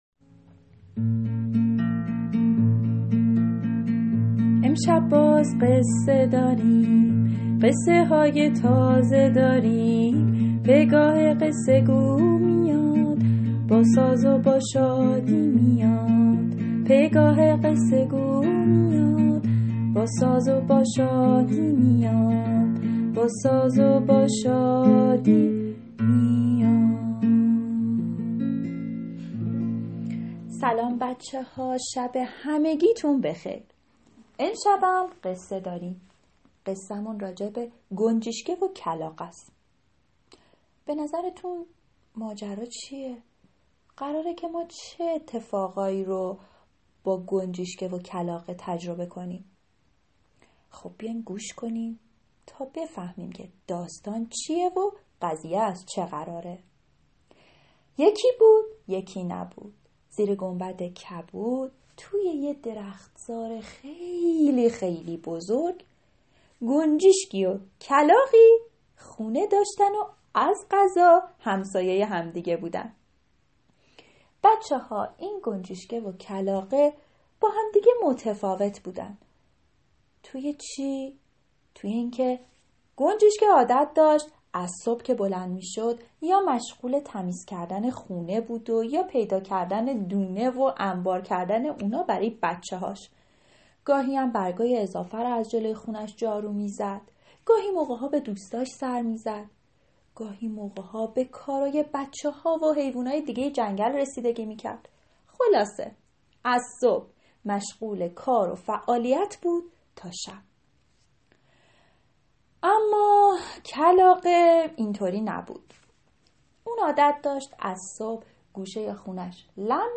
قصه صوتی
قصه صوتی کودکان دیدگاه شما 915 بازدید